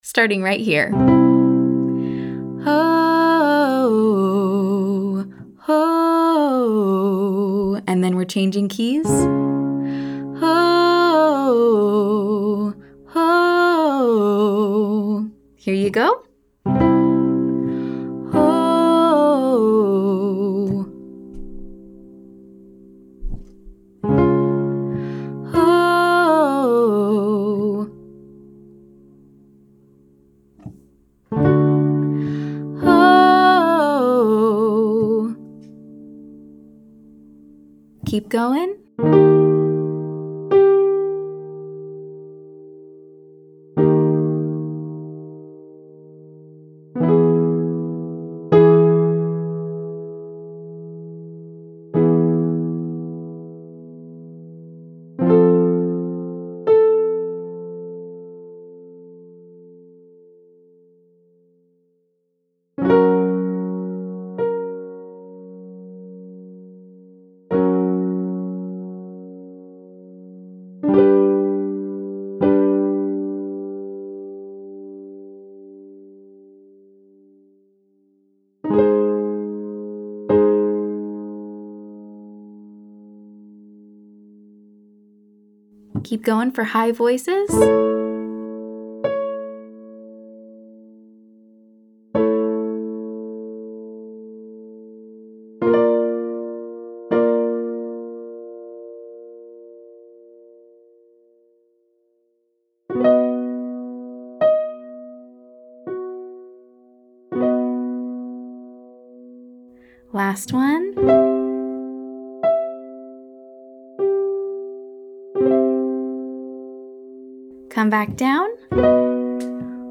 We’ll sing the pattern slowly, then riff.
Exercise 2: 32165 (slow), 32165 (riff)